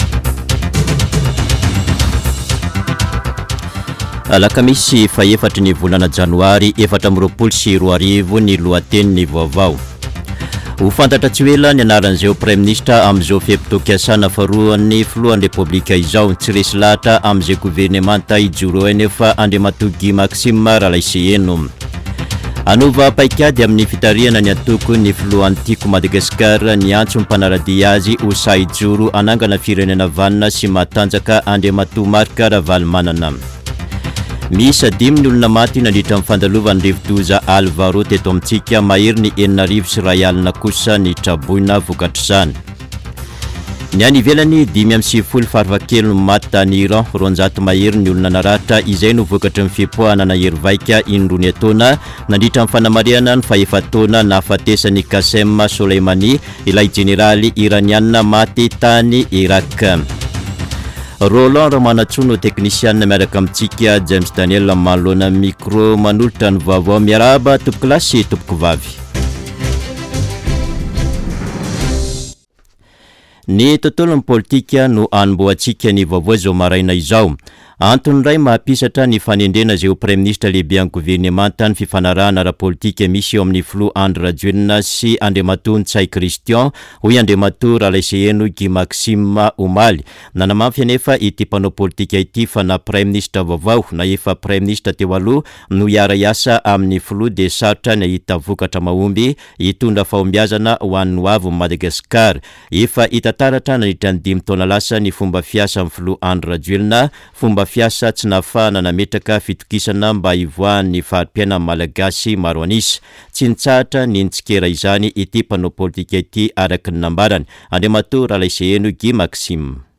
[Vaovao maraina] Alakamisy 4 janoary 2024